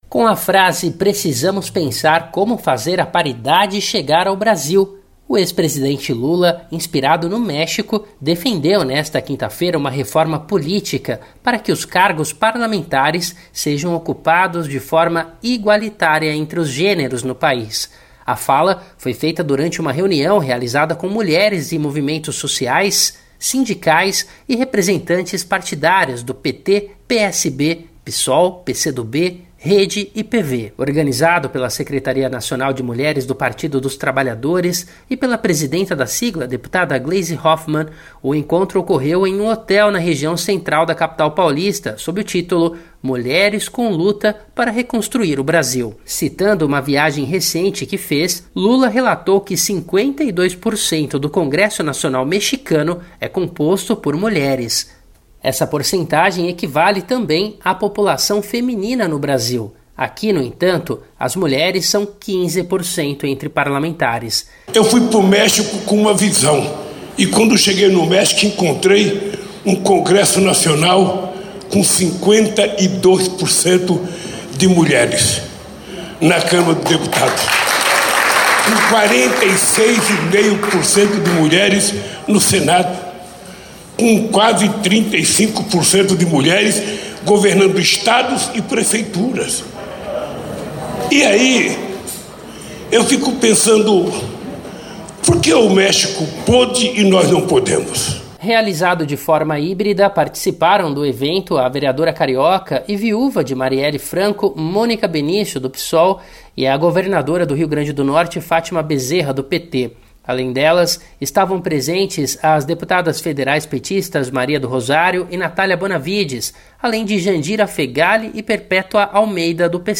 Em evento com mulheres, Lula defende reforma política com paridade de gênero para parlamentares
A fala foi feita durante uma reunião realizada, na manhã desta quinta-feira (10), com mulheres de movimentos sociais, sindicais e representantes partidárias do PT, PSB, PSOL, PCdoB, Rede e PV.